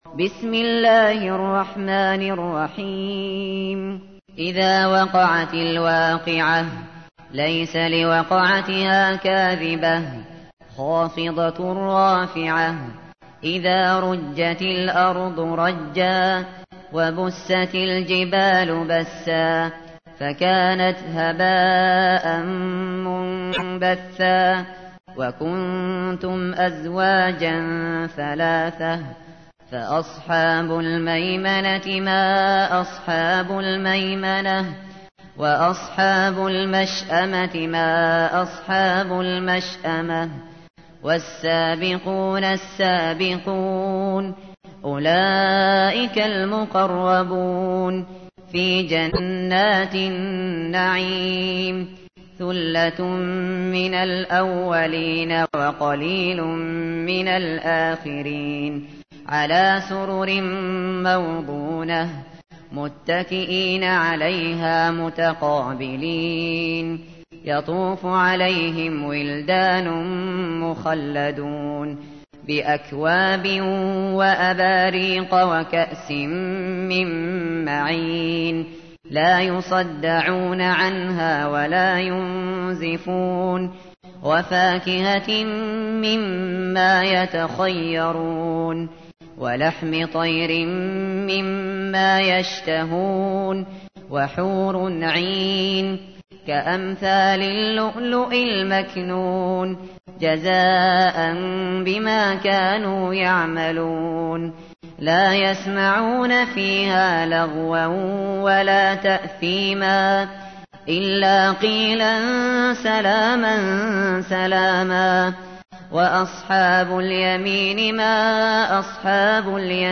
تحميل : 56. سورة الواقعة / القارئ الشاطري / القرآن الكريم / موقع يا حسين